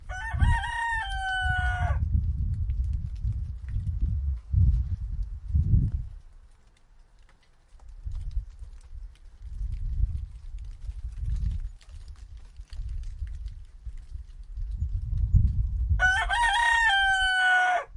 自然 " 公鸡鸣叫
记录SAMSUNGE2252SOUND未经编辑，但在2010年10月2日在印度喀拉拉邦的VANIYAMBALAM地区进行了不受欢迎的调查前后的调查
标签： 公鸡 鸡鸣 鸟呼叫 公鸡 性质 现场录音